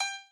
b_piano3_v100l8o6g.ogg